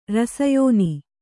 ♪ rasa yōni